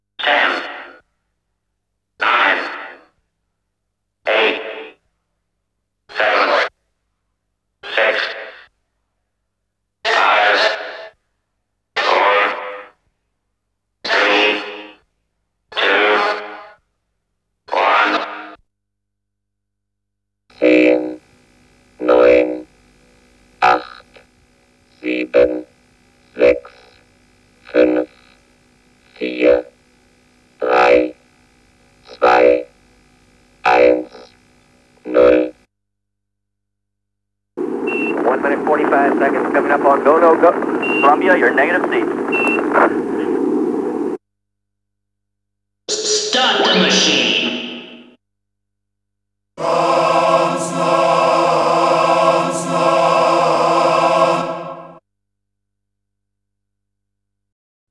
36 Countdown _ Space.wav